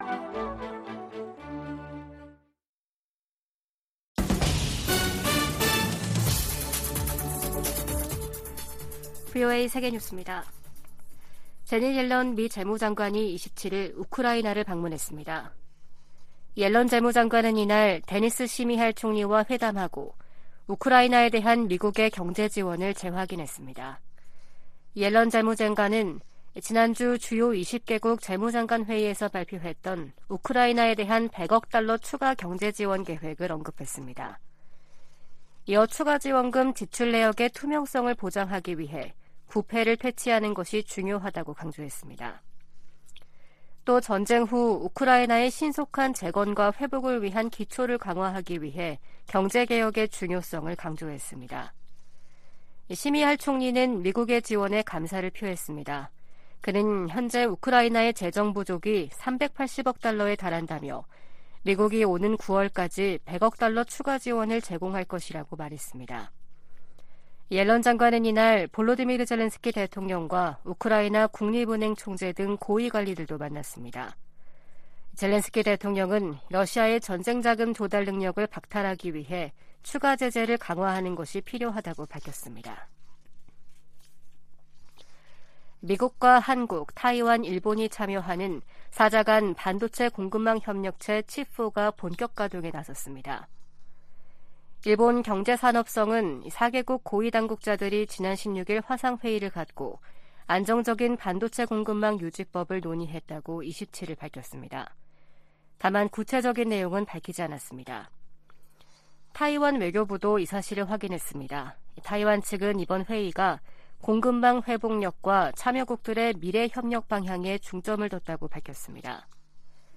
VOA 한국어 아침 뉴스 프로그램 '워싱턴 뉴스 광장' 2023년 2월 28일 방송입니다. 백악관은 러시아 용병조직 바그너 그룹에 북한이 무기를 지원했다고 거듭 비판했습니다. 미 국방부는 중국이 러시아 지원 카드를 완전히 내려놓지 않았다며 예의주시할 것이라고 밝혔습니다. 남-북한은 유엔총회에서 바그너 그룹에 대한 북한의 무기거래 문제로 설전을 벌였습니다.